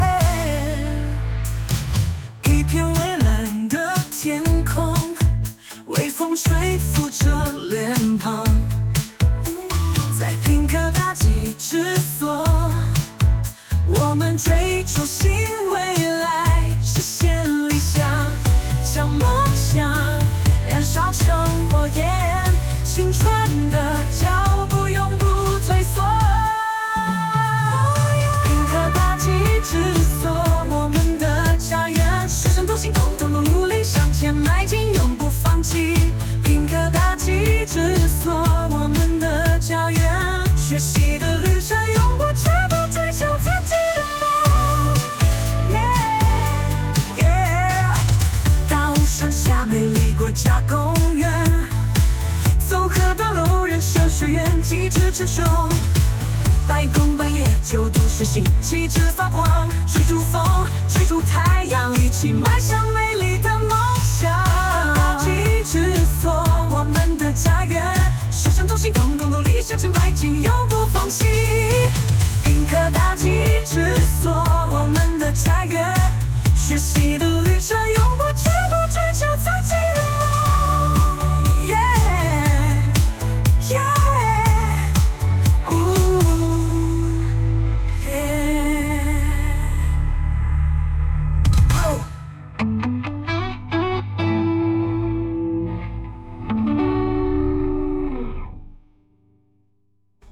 二、本所所歌